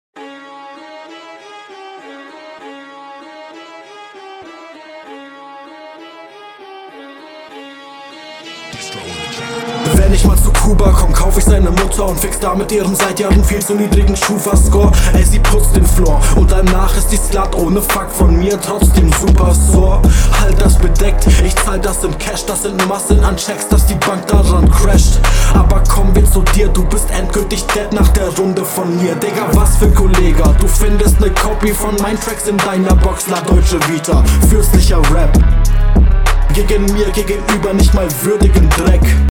Endlich versteht man dich mal halbwegs gut.